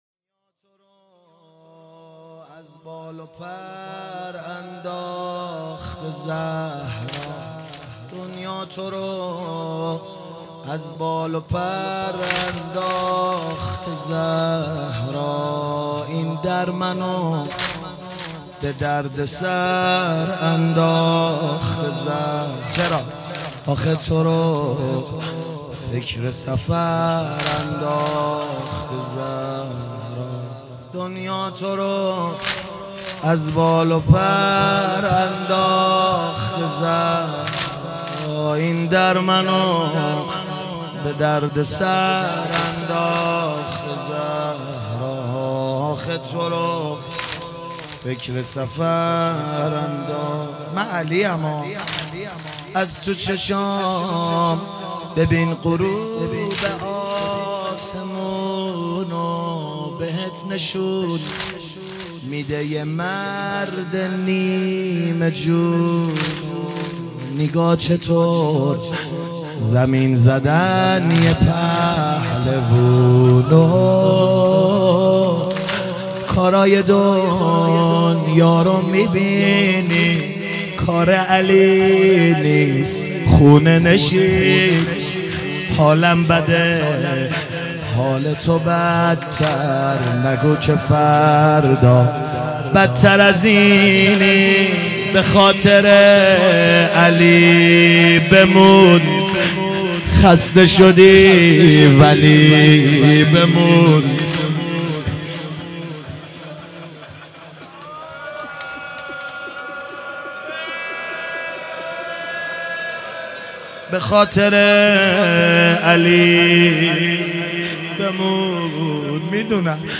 مداحی و نوحه
مداحی فاطمیه